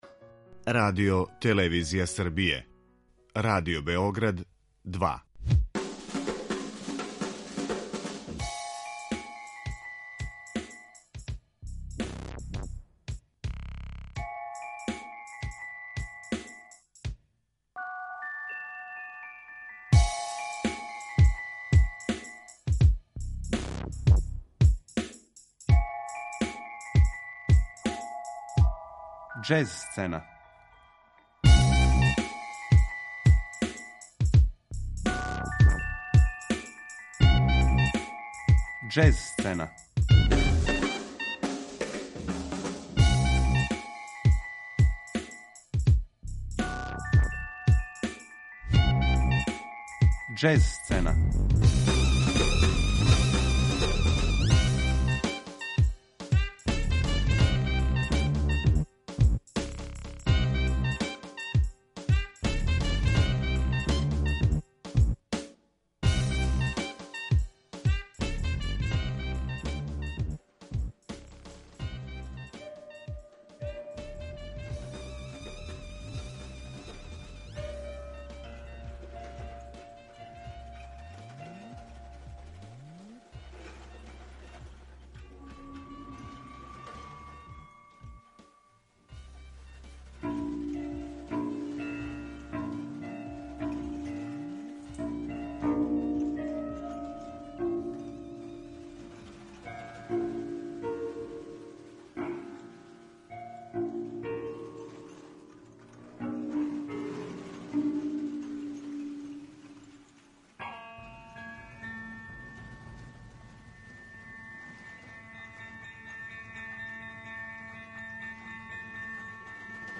Ове суботе најављујемо 26. издање међународног фестивала нове музике Ринг Ринг, који се од 19. до 26. маја одржава у Београду. Слушаћемо одабрану музику учесника ове манифестације.